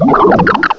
sovereignx/sound/direct_sound_samples/cries/duosion.aif at master